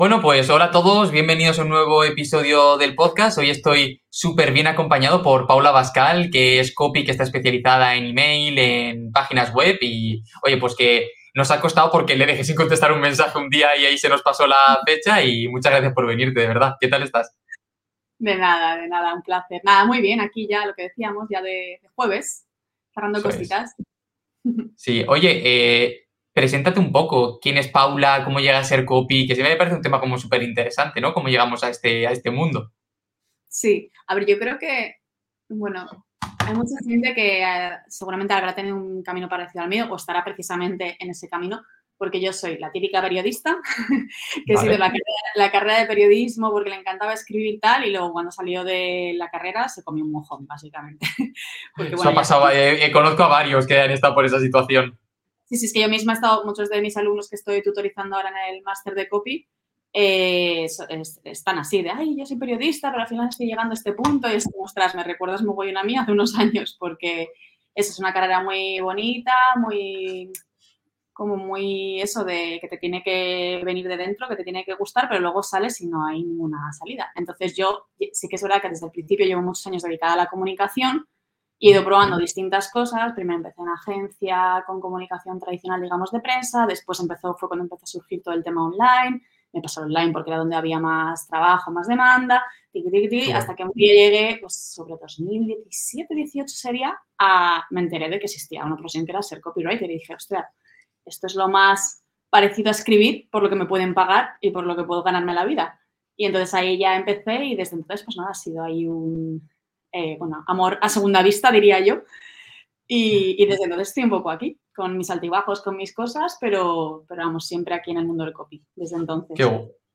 Copymelo Entrevista